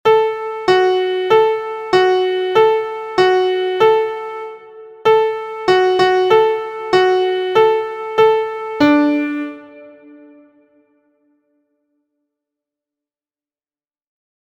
• Origin: USA – Jump Rope Song
• Key: G Major
• Time: 4/4
• Form: AB
• Pitches: beginners: Do Mi So
• Key Words: playground song, jump-rope song, double dutch, doctor, die, sick, jumping rope, counting one (1) thru…